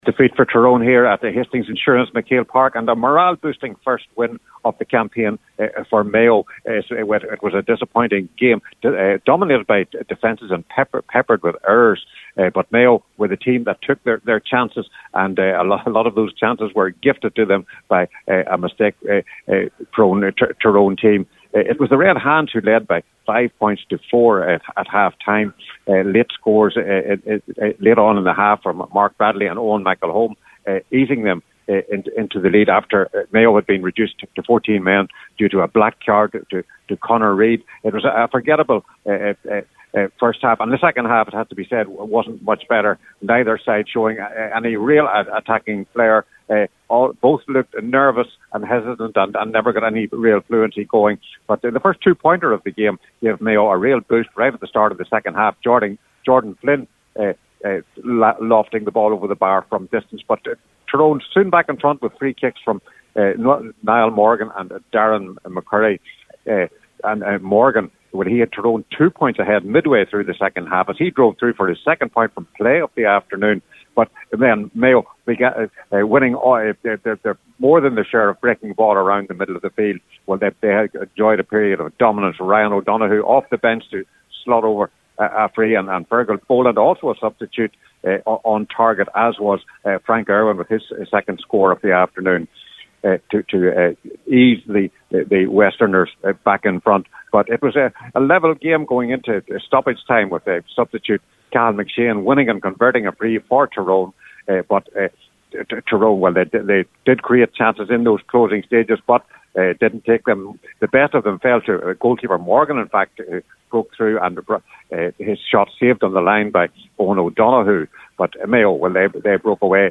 With the full time report for Highland Radio